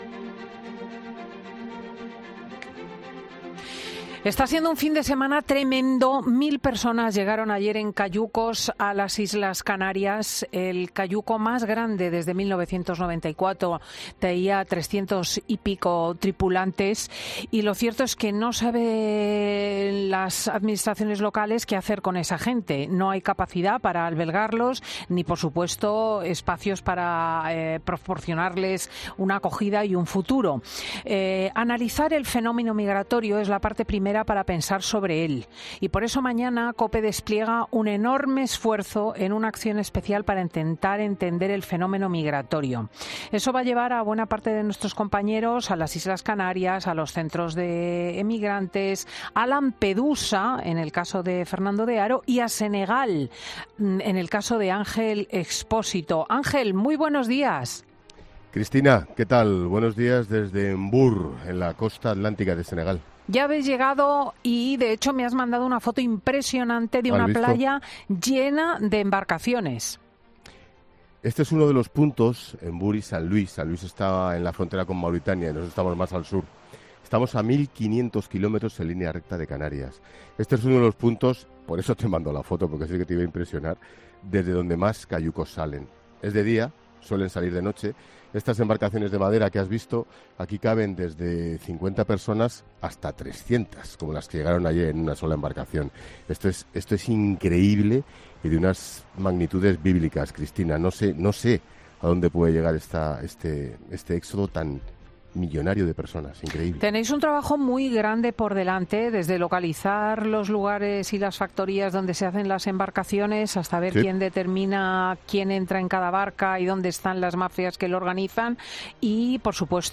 El director de 'La Linterna' está en Senegal analizando el drama migratorio y ahí ha podido hablar con una mujer que conoce de cerca lo que significa